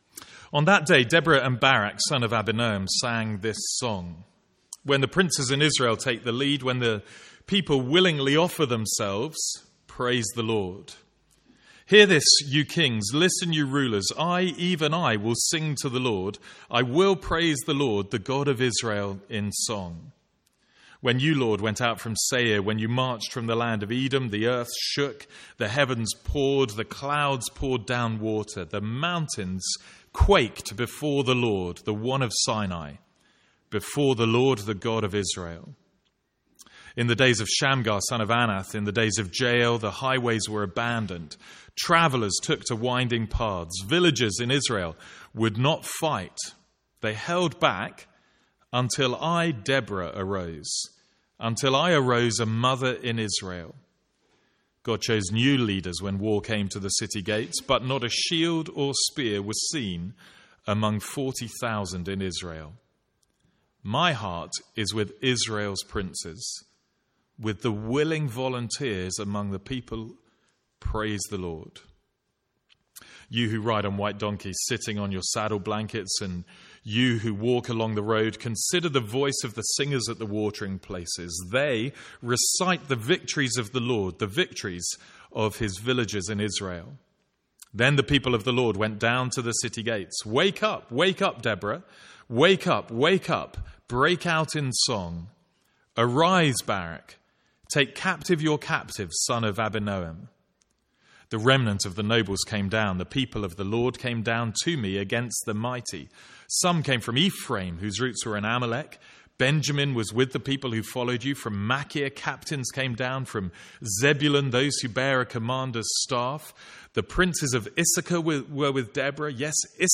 Sermons | St Andrews Free Church
From the Sunday morning series in Judges.